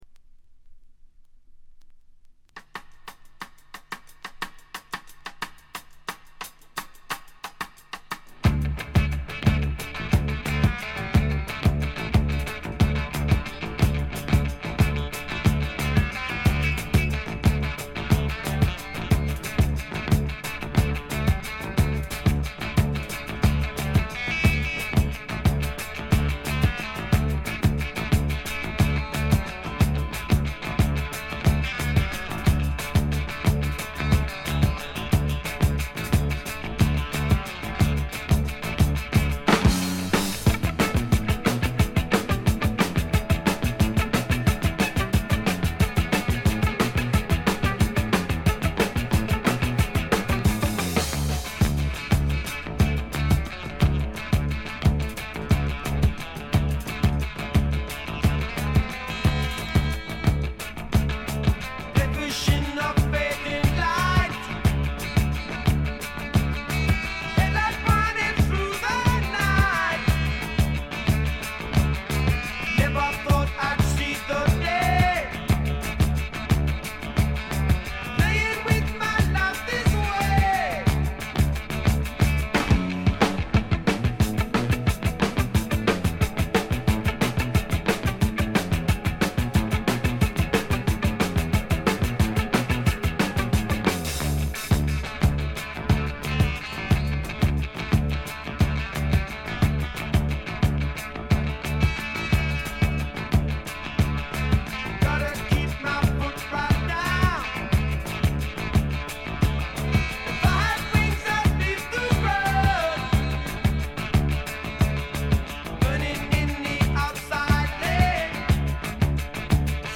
これ以外はほとんどノイズ感無し。
試聴曲は現品からの取り込み音源です。
Recorded At - Surrey Sound Studios